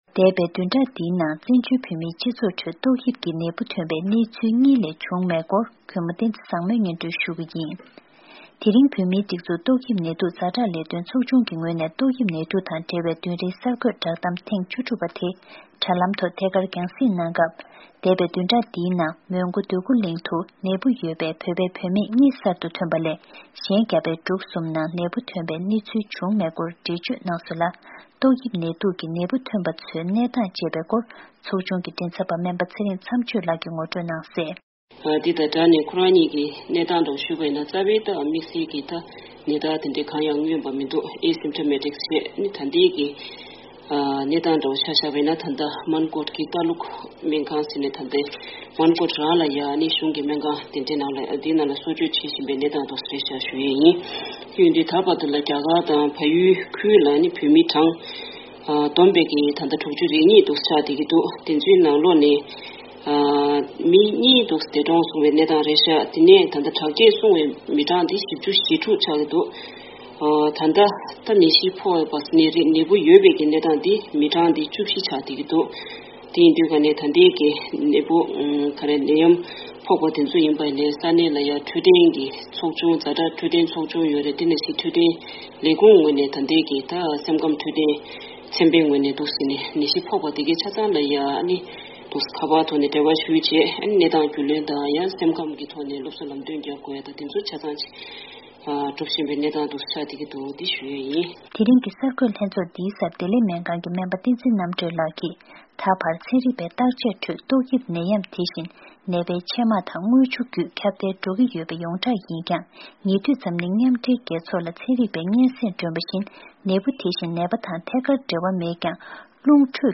བོད་མིའི་སྒྲིག་འཛུགས་ཀྱི་བདུན་རེའི་ཏོག་ནད་གསར་འགོད་ལྷན་ཚོགས།